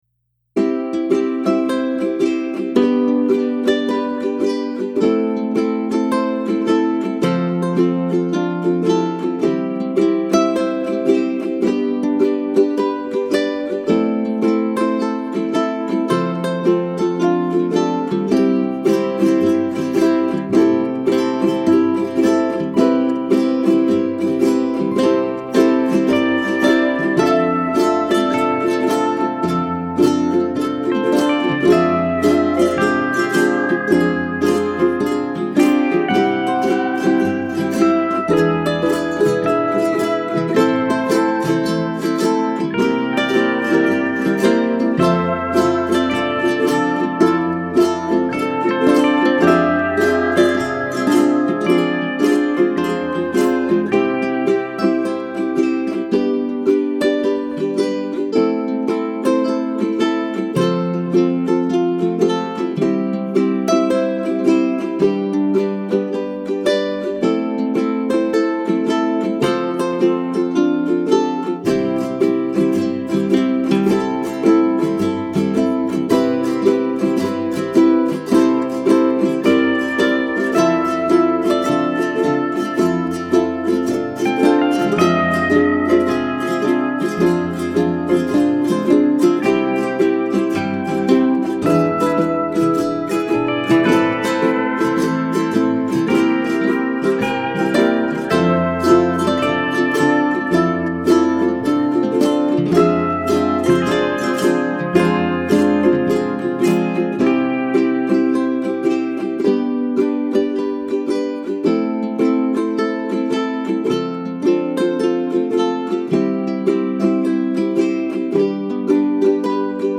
Ukulele_Beach.mp3